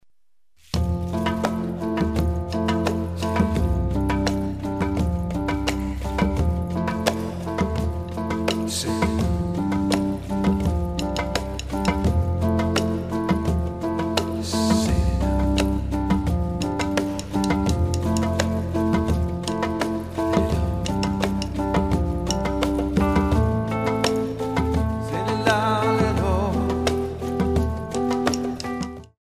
Modern Dance CD
10 Pieces for Keyboard, accordian, and percussion.
Nice atmospheres with fluid textures